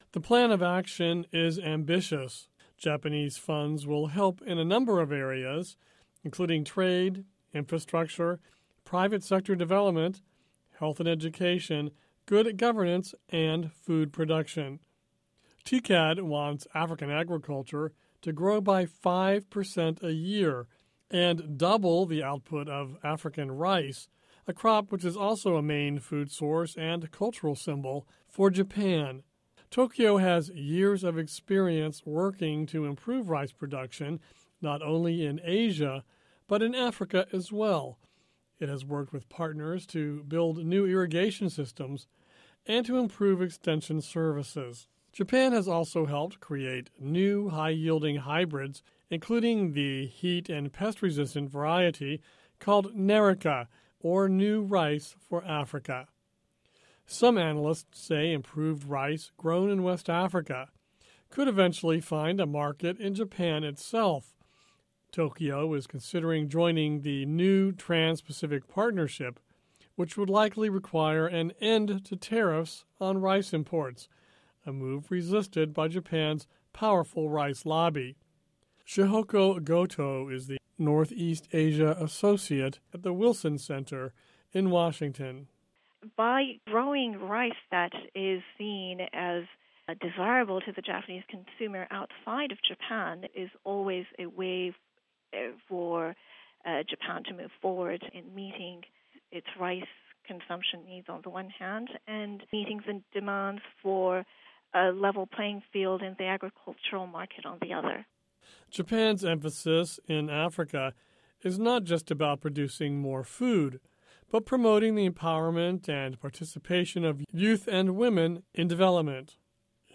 Listen to report on Japanese investment in African infrastructure